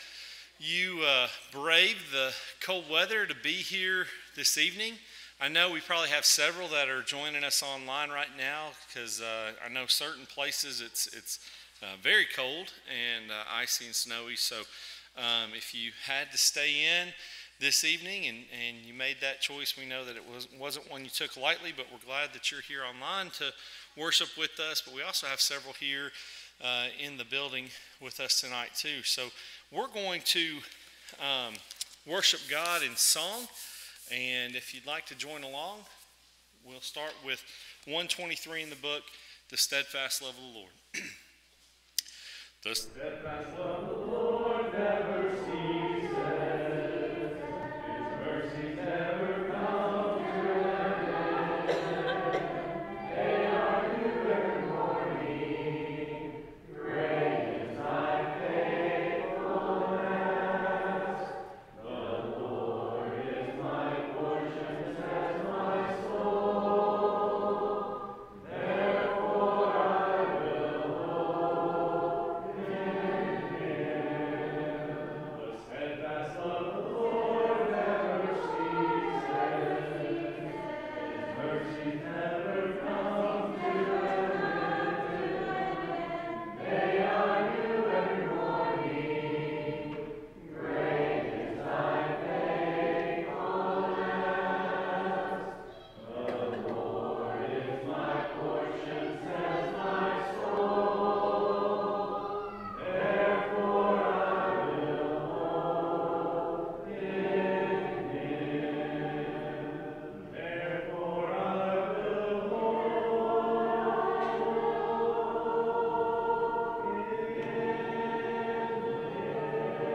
Luke 18:1, English Standard Version Series: Sunday PM Service